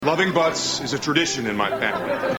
Category: Comedians   Right: Personal